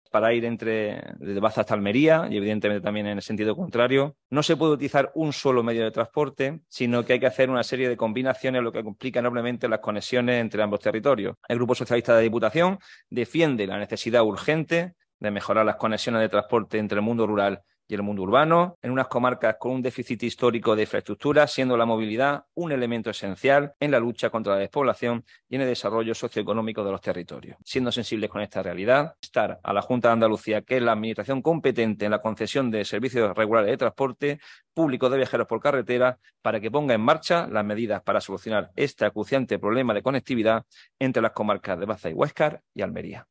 Este es el titular del comunicado que nos ha remitido el PSOE granadino en relación a este asunto, el cual reproducimos a continuación, junto a la nota de voz que lo acompaña y en la que Juan Francisco Torregrosa asegura que las opciones existentes en la actualidad “impiden utilizar un solo medio de transporte y obliga a los usuarios a emplear demasiado tiempo en el desplazamiento”: